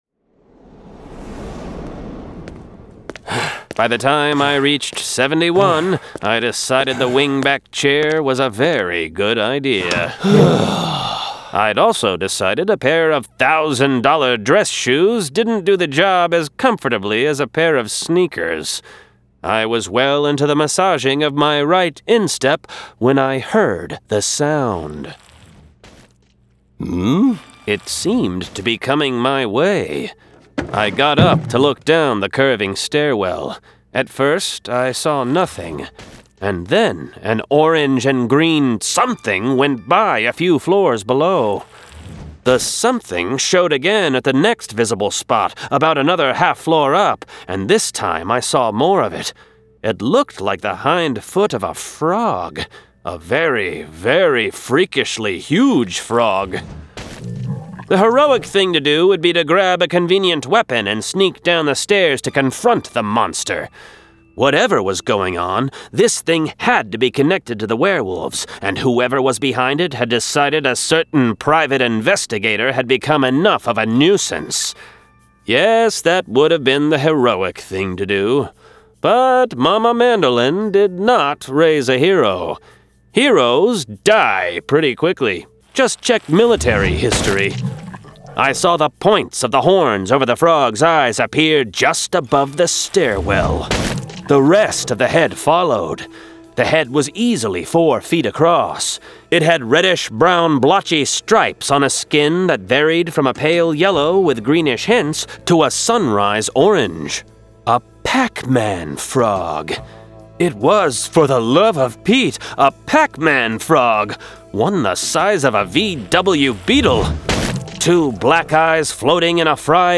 Full Cast. Cinematic Music. Sound Effects.
[Dramatized Adaptation]
Genre: Urban Fantasy